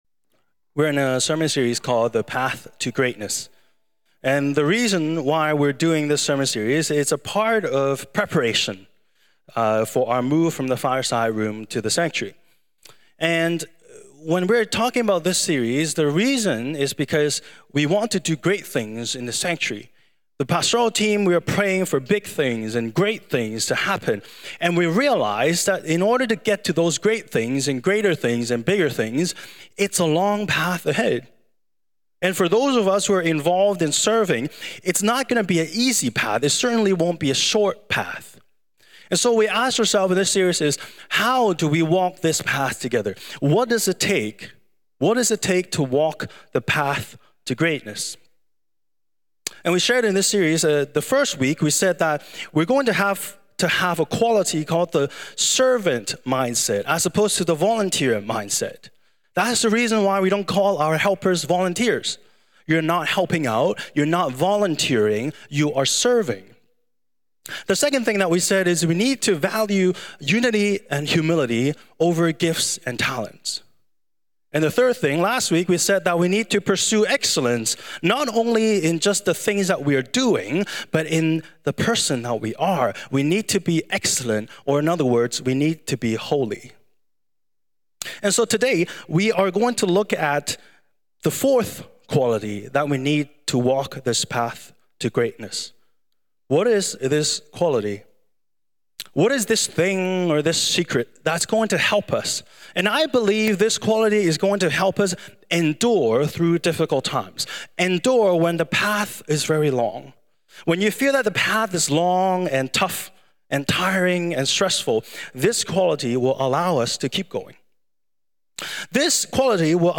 Sermons | Koinonia Evangelical Church | Live Different!